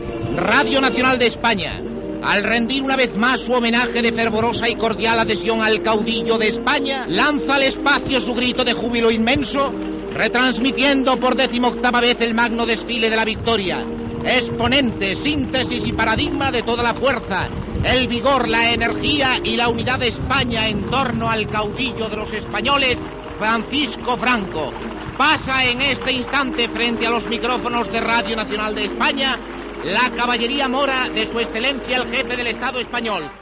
Fragment de la transmissió del "XVIII Desfile de la Victoria" des de Madrid.
Informatiu